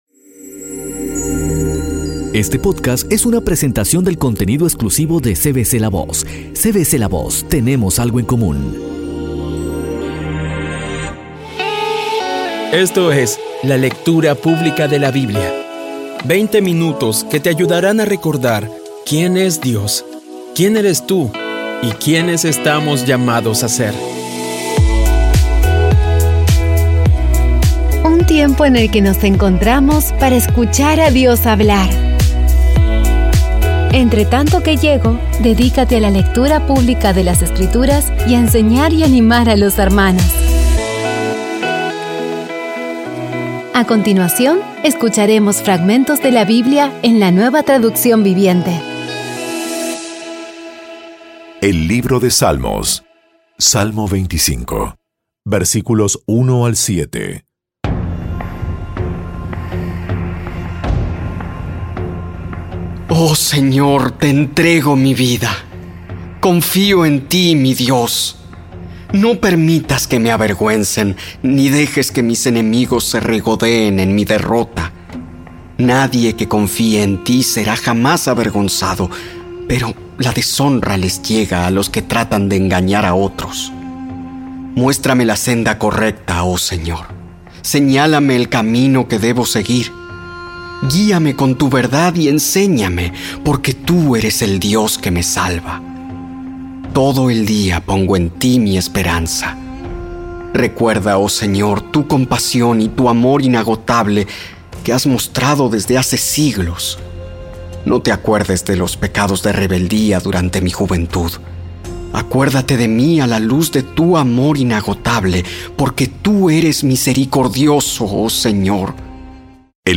Audio Biblia Dramatizada Episodio 48
Poco a poco y con las maravillosas voces actuadas de los protagonistas vas degustando las palabras de esa guía que Dios nos dio.